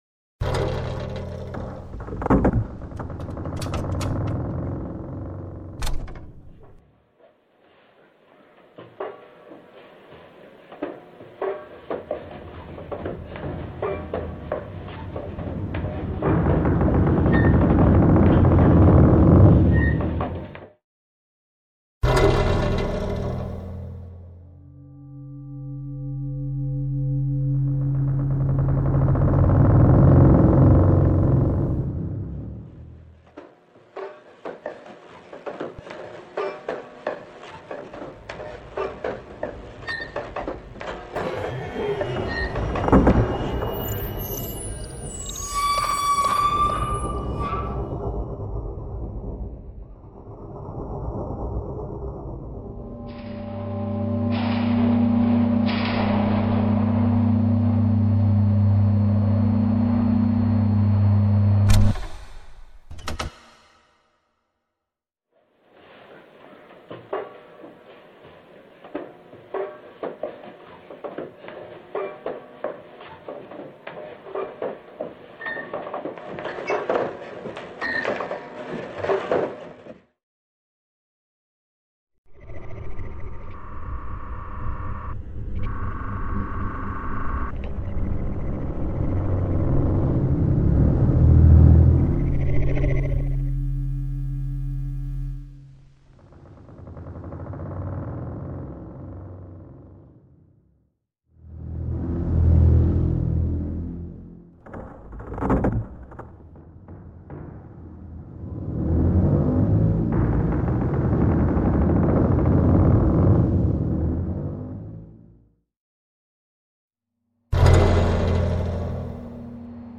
Das stumme Geläut - Klanginstallation
Bespielt wird der Treppenbereich und evtl. der Sockel des Denkmals. Aus 7 in Bodennischen versenkten Lautsprechern ist das stumme Geläut erlebbar.
Zu hören ist die riesengrosse Anstrengung des Aufschaukelns der tonnenschweren Glocken, das Knarren, Ächzen und Poltern der hölzernen Joche, das wummernde Keuchen der Glockenturmtechnik. Die Glocken kommen aber nicht zum Schwingen und Klingen, sie bleiben stumm.